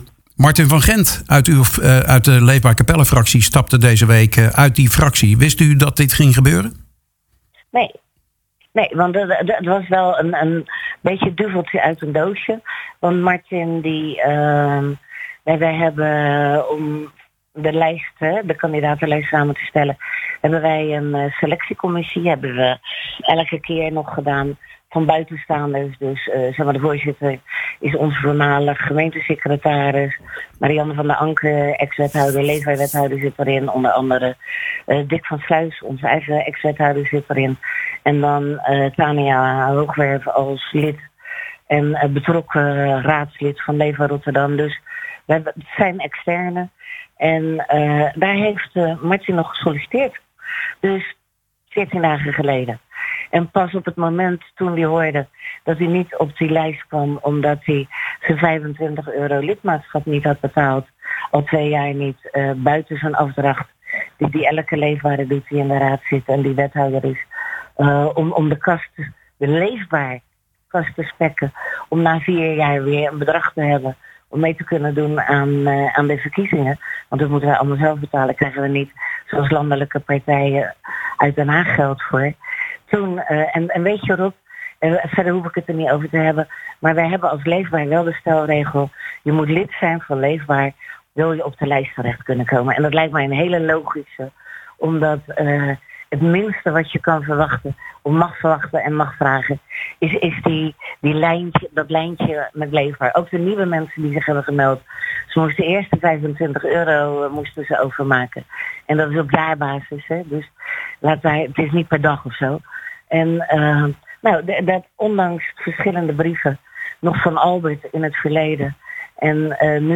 in gesprek